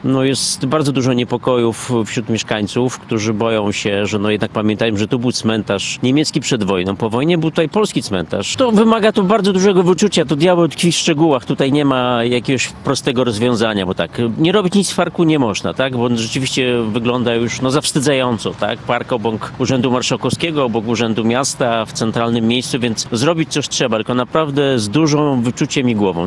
– Należy zachować szacunek do tego miejsca – podkreśla Janusz Rewers, miejski radny: